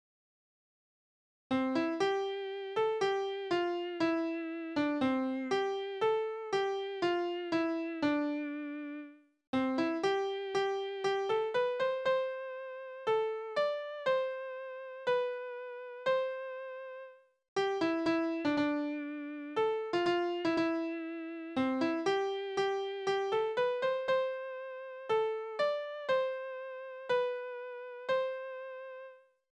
Tonart: C-Dur
Taktart: 4/4
Tonumfang: große None
Besetzung: vokal